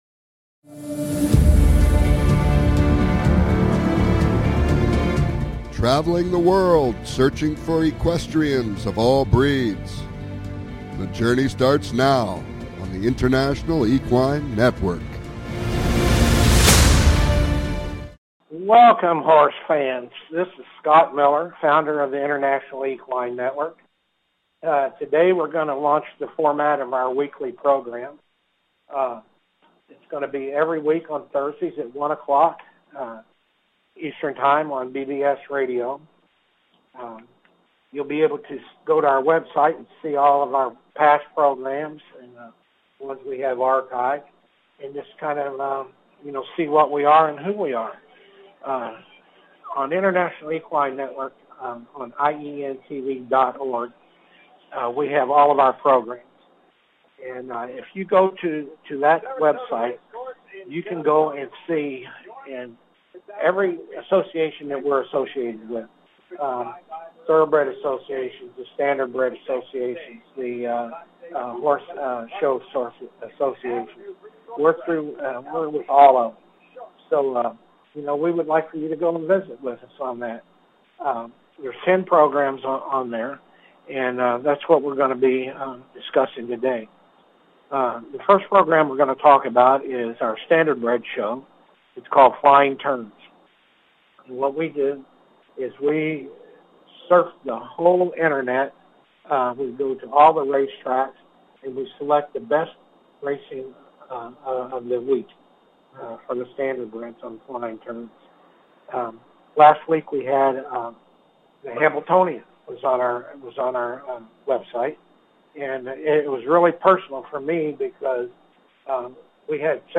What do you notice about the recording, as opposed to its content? This show will bring you the inside scoop! Calls-ins are encouraged!